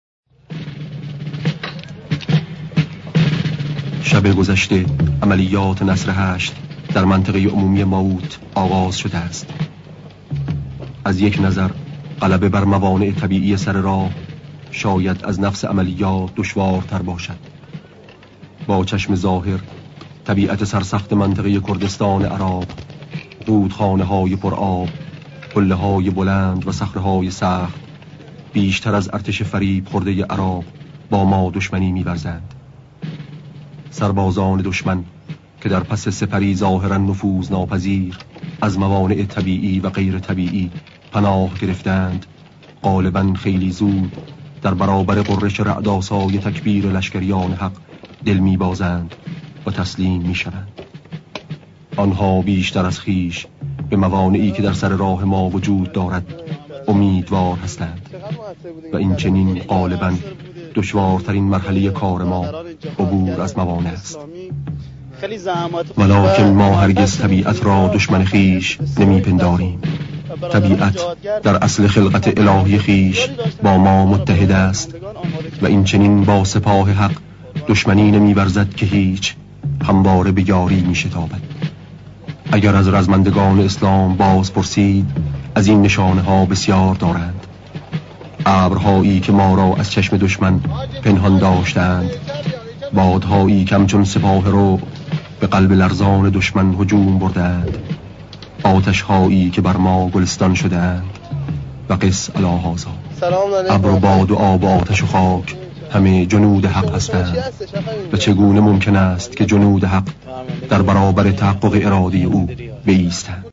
صدای شهید آوینی/ با چشم ظاهر طبیعت سرسخت کردستان عراق بیشتر از دشمن عراق با ما دشمنی میورزد.